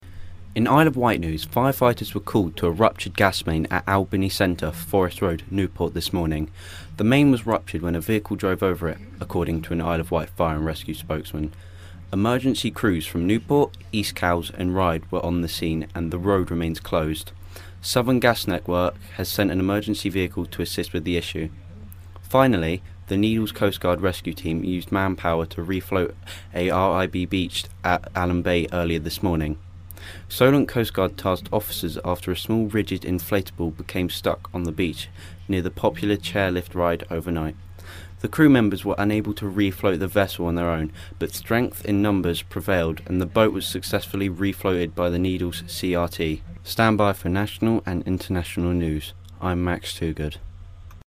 reads the news Monday